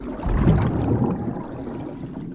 diving_swim_loop.ogg